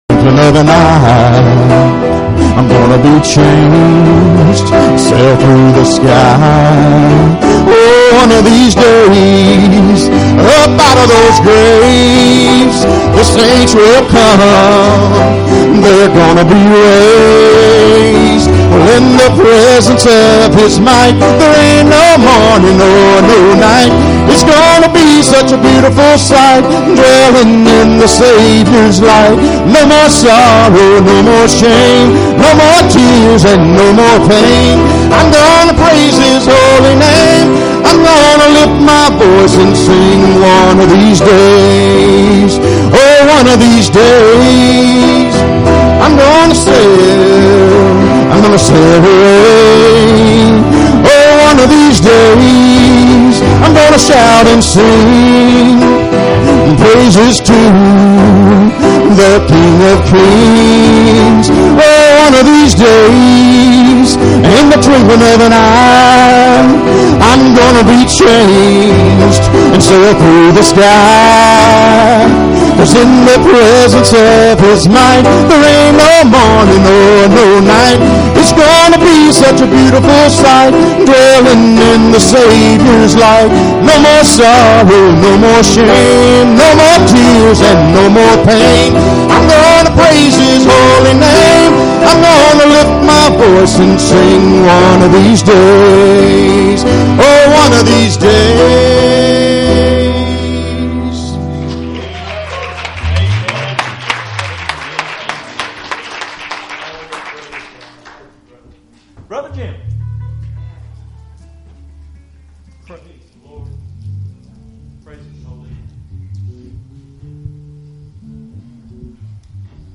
Passage: Ephesians 2:1-7 Service Type: Sunday Evening Services Topics